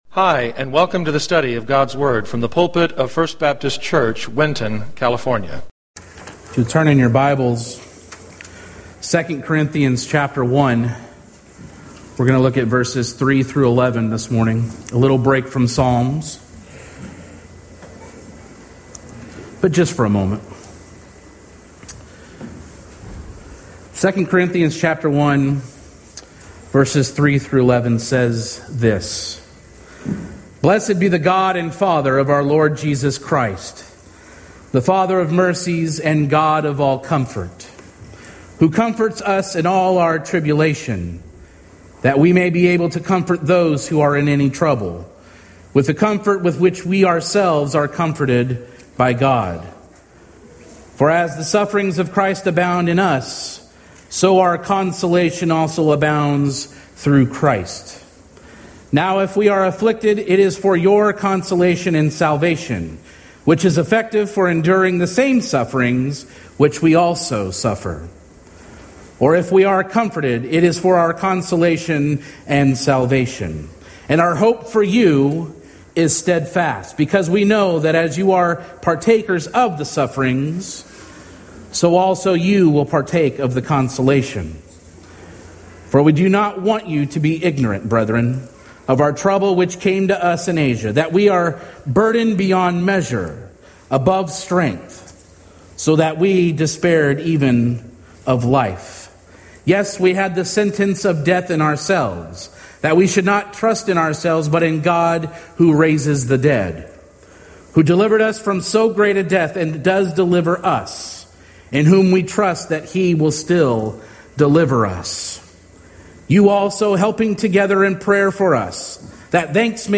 Winton First Southern Baptist Church Sermons podcast